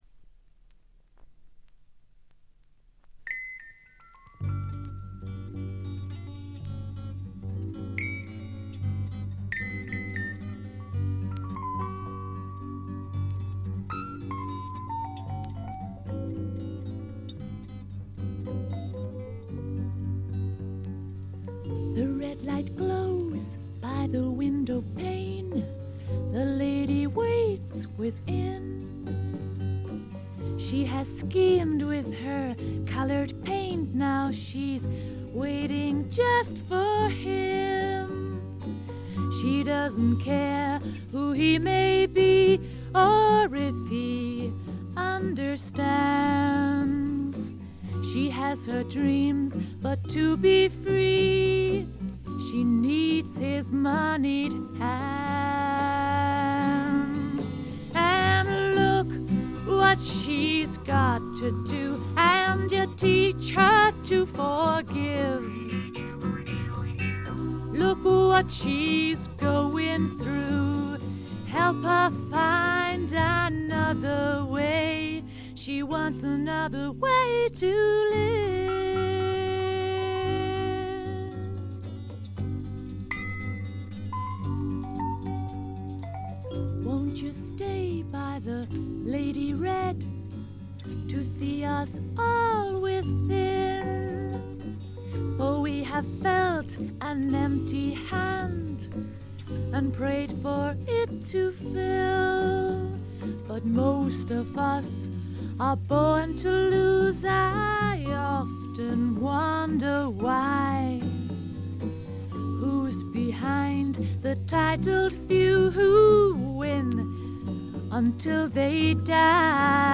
ホーム > レコード：英国 SSW / フォークロック
試聴曲は現品からの取り込み音源です。